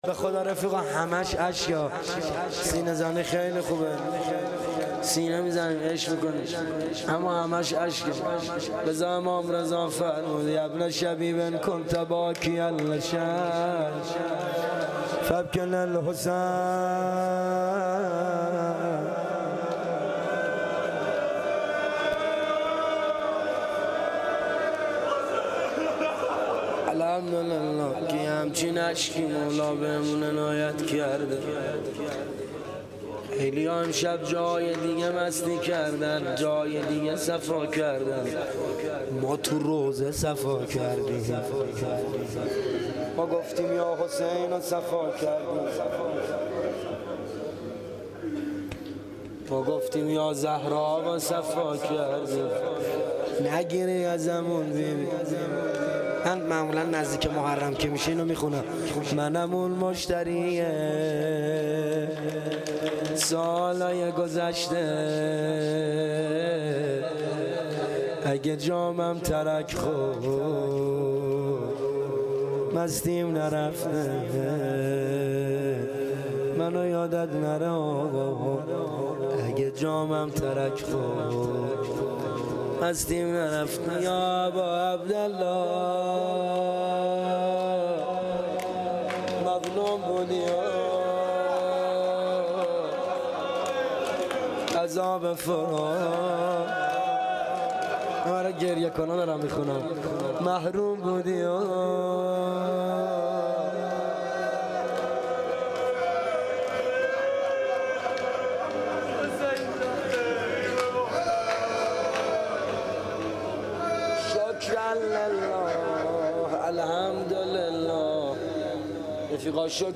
هیئت معظم یا زهرا سلام الله علیها
روضه ی پایانی